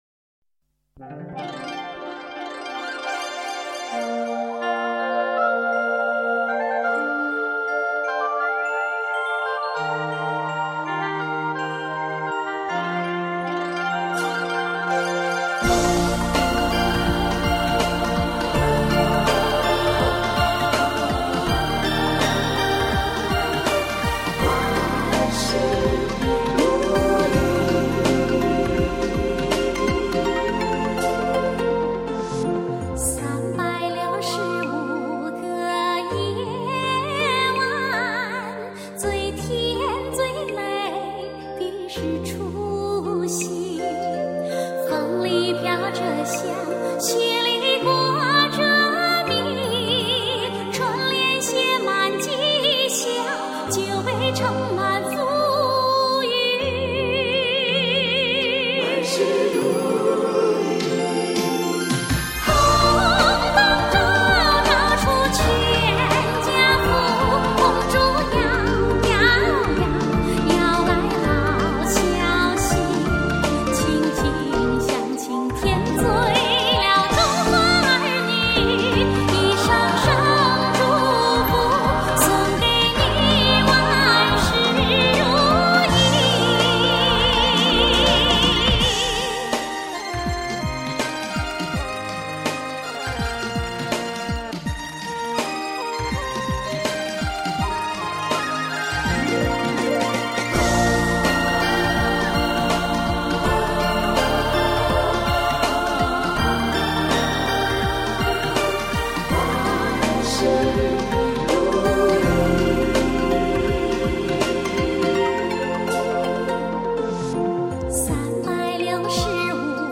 Chanson traditionnelle